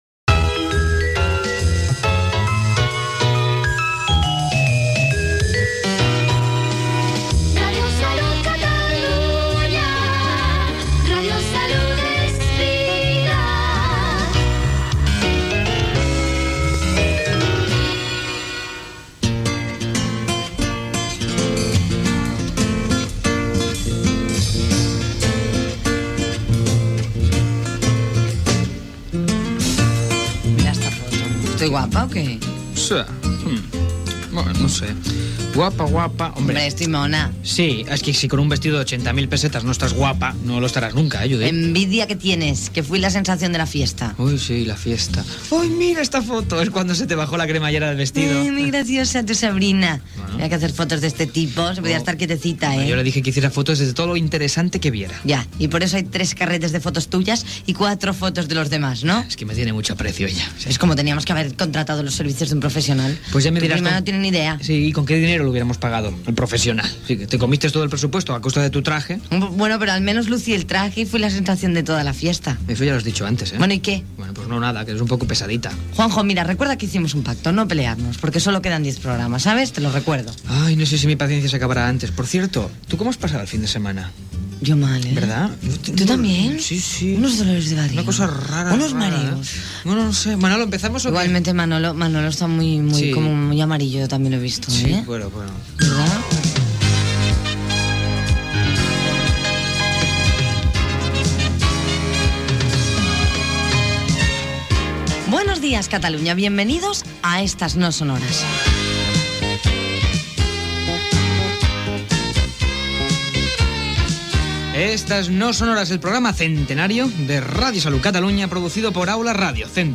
Indicatiu de l'emissora, diàleg sobre una festa i el cap de setmana, presentació, equip, sumari del programa, notícies curioses amb el Quiosco Euronews Gènere radiofònic Entreteniment